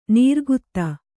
♪ nīrgutta